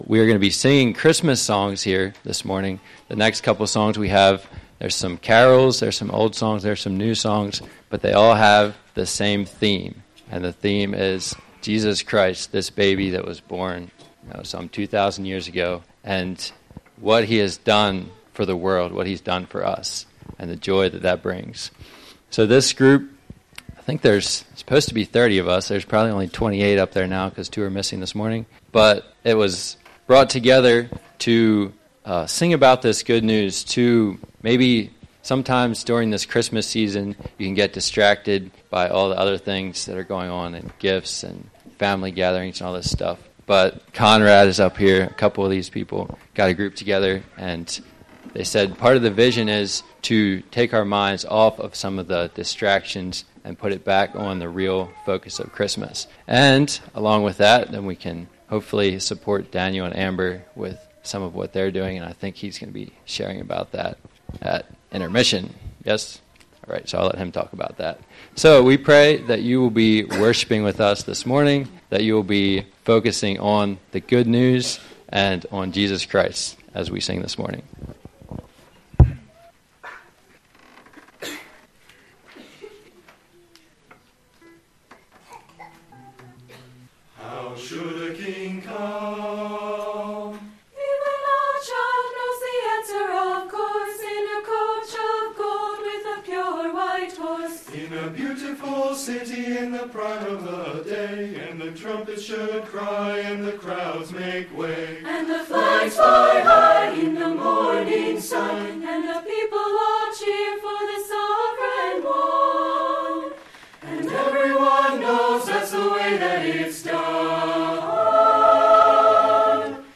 Lancaster Christmas Choir